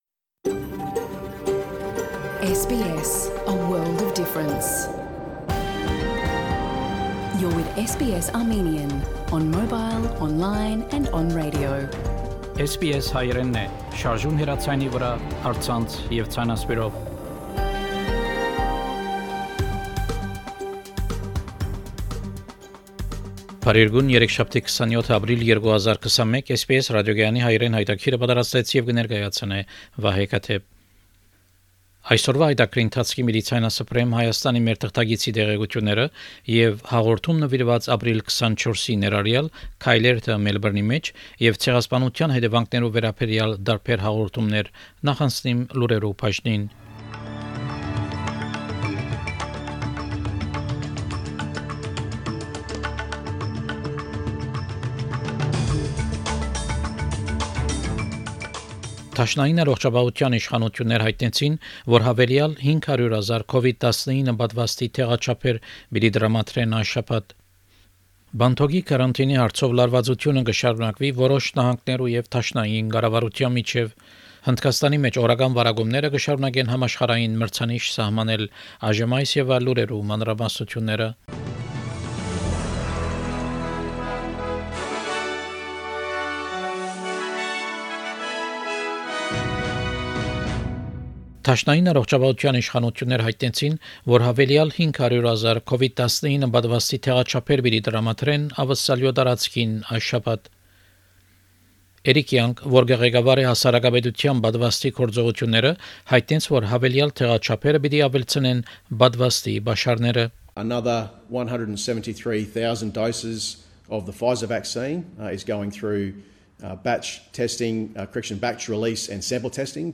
SBS Armenian news bulletin – 27 April 2021
SBS Armenian news bulletin from 27 April 2021 program.